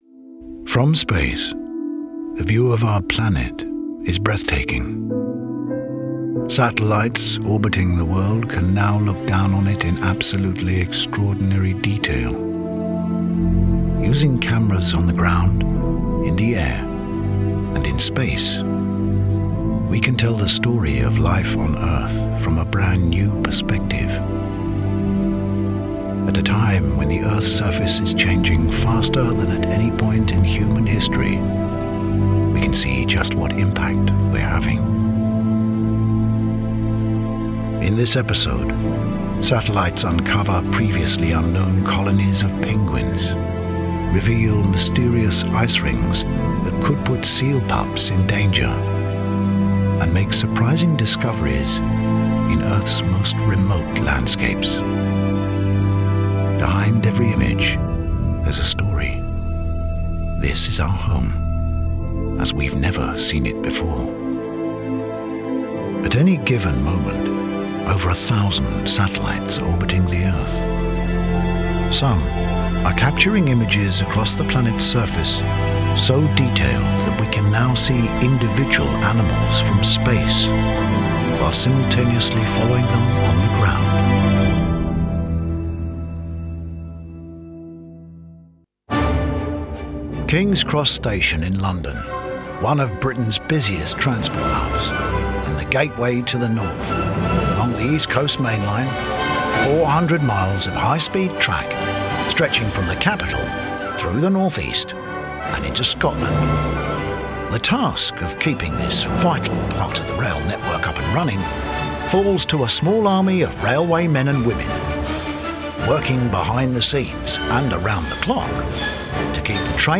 Deep, authoritative, confident. Actor.